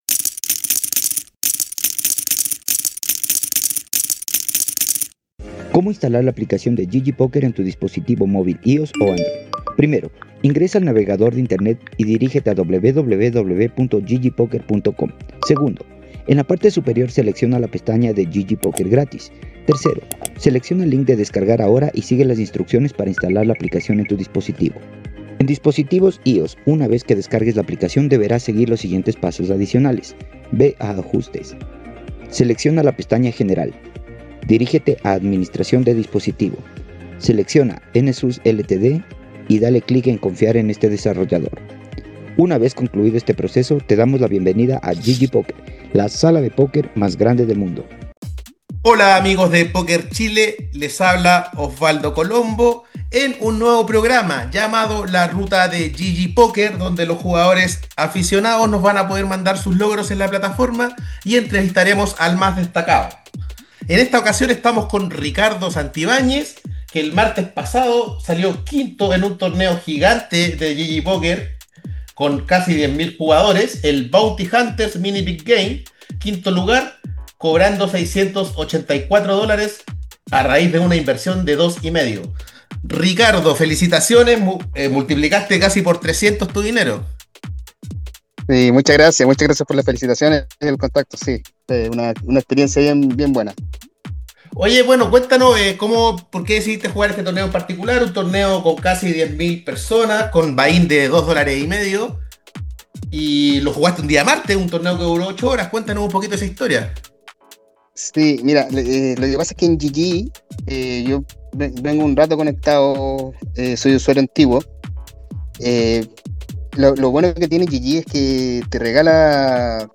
Nace un nuevo proyecto en PokerChile, se trata de “La ruta de GGPoker”, un podcast de entrevistas donde conversamos con jugadores aficionados que hayan llegado arriba en algún torneo de la plataforma.